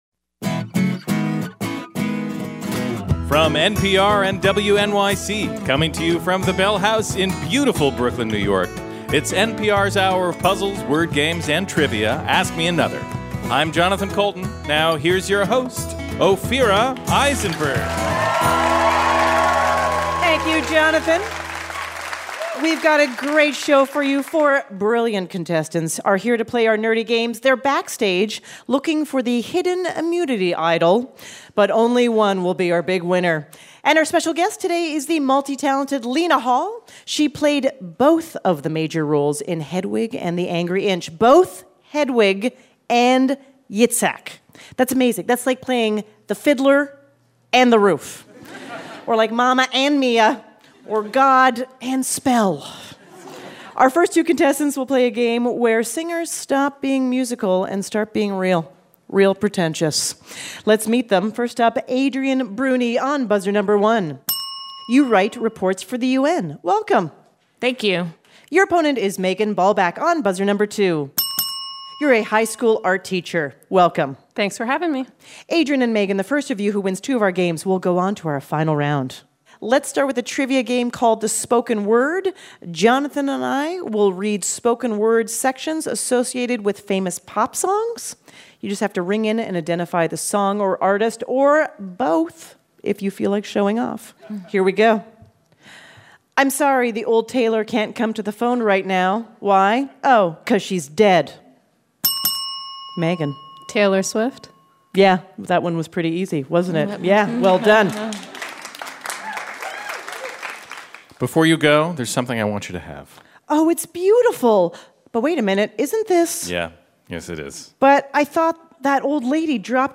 Advertisement Lena Hall: From Yitzhak to Hedwig Play 52:14 Download Audio March 30, 2018 facebook Email Lena Hall, who won a Tony for her performance in Hedwig and the Angry Inch, guests on NPR's Ask Me Another at The Bell House in Brooklyn, New York.